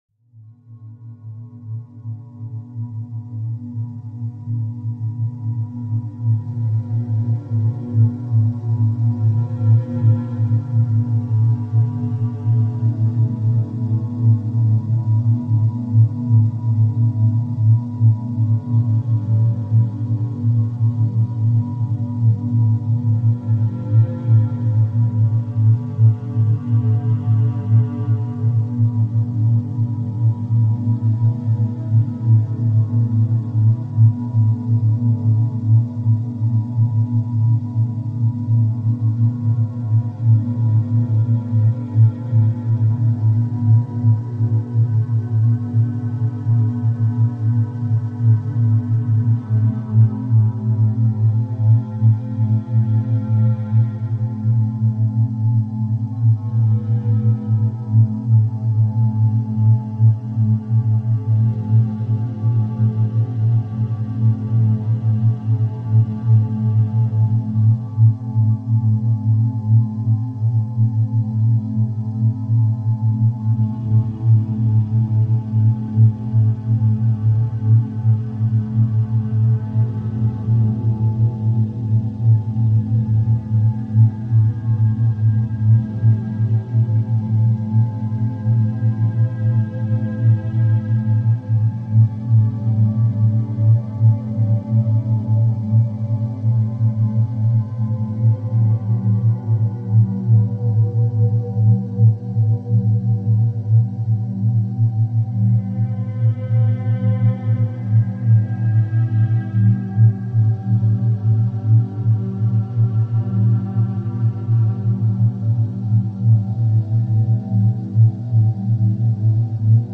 Étude piano structurée · méthode éprouvée de focus 2 heures